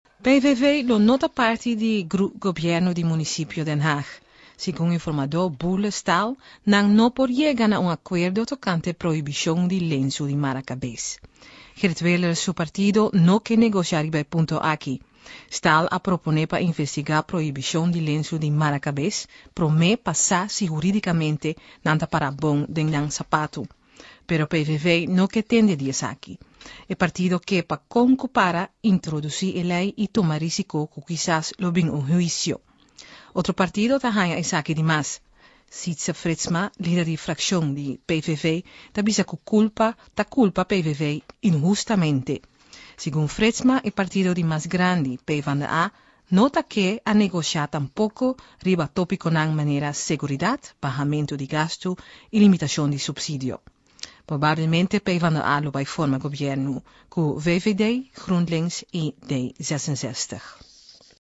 28 March 2010 at 9:45 pm Iberian Romance with lots of Dutch names pronounced in Dutch and a few Caribbean references.
The recording comes from Radio Nederland Wereldomroep
“PVV” is pronounced exactly as in Dutch.